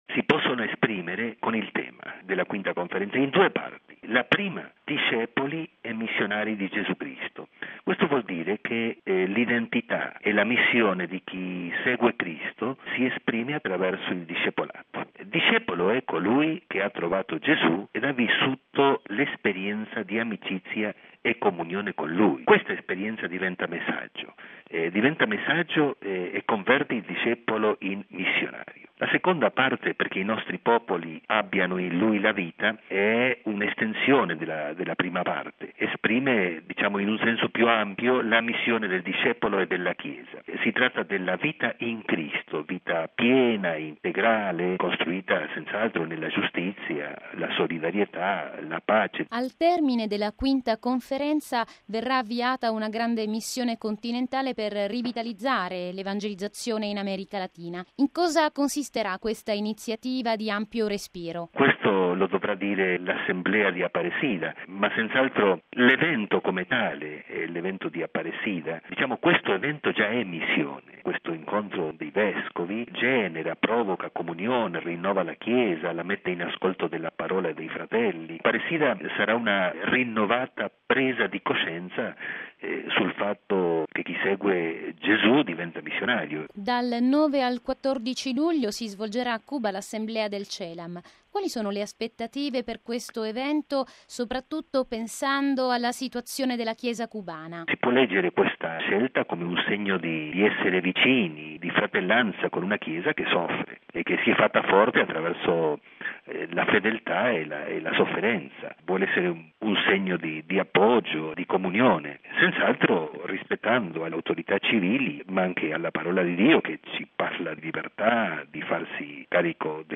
I punti forti di questa conferenza di Aparecida sono già delineati nel tema scelto dal Papa: “Discepoli e missionari di Gesù Cristo, perché in Lui i nostri popoli abbiano vita”. A sottolinearlo è il segretario generale del CELAM, il vescovo argentino di Reconquista Andres Stanovnik,